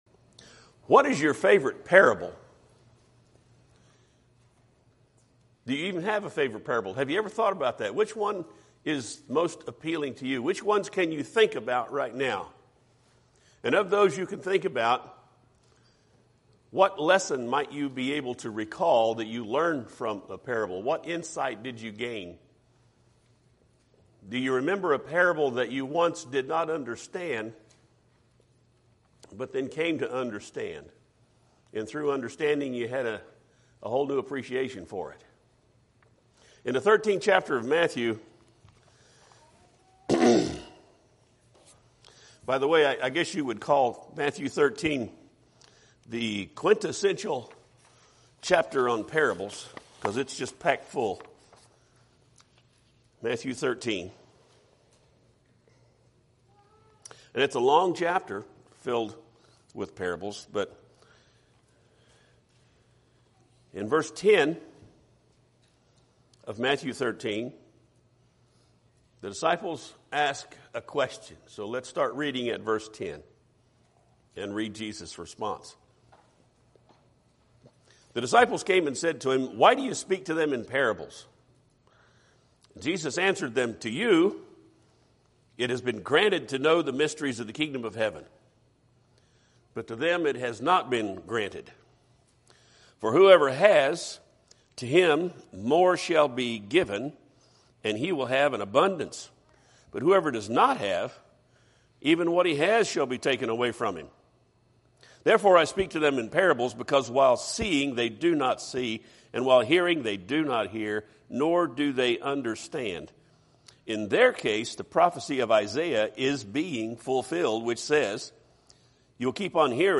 Podcasts Videos Series Sermons Why Parables?